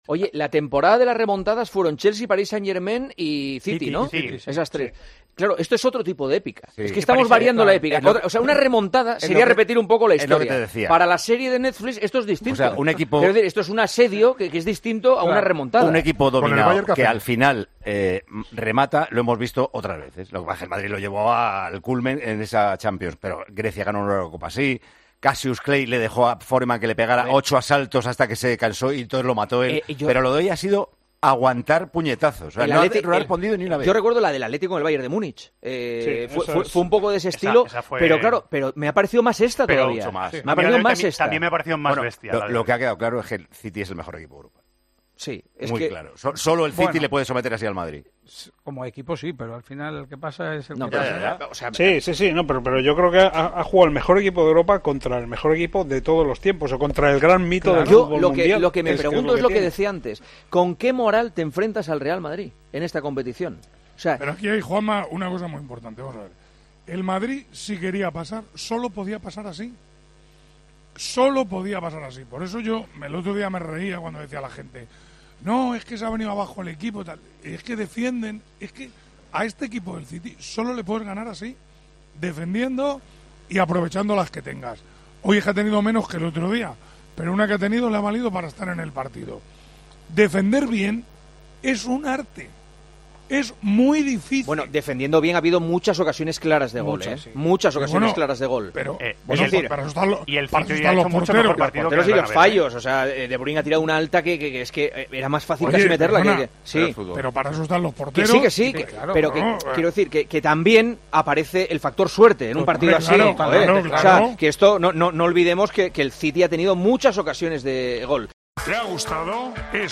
El narrador de Tiempo de Juego valoró en El Partidazo de COPE el partido que realizó el equipo de Carlo Ancelotti en el Etihad Stadium.